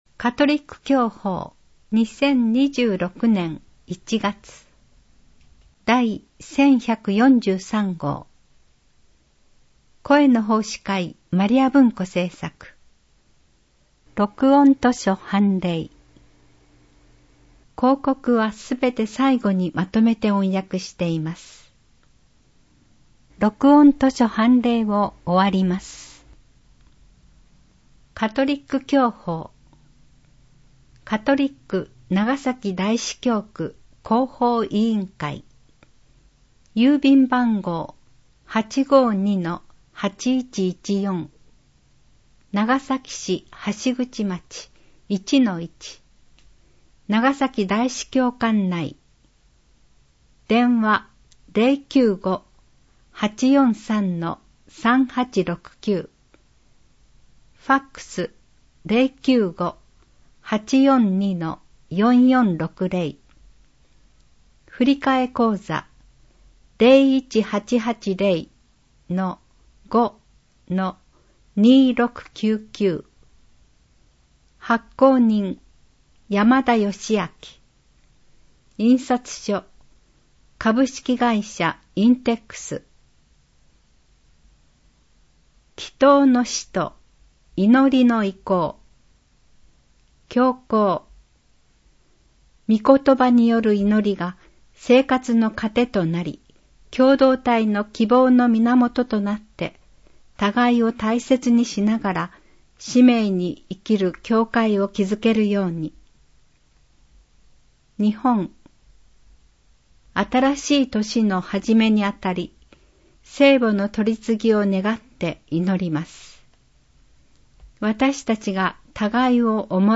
【音声訳】2026年1月号